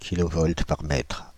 Ääntäminen
Ääntäminen France (Île-de-France): IPA: /ki.lɔ.vɔlt paʁ mɛtʁ/ Haettu sana löytyi näillä lähdekielillä: ranska Käännöksiä ei löytynyt valitulle kohdekielelle.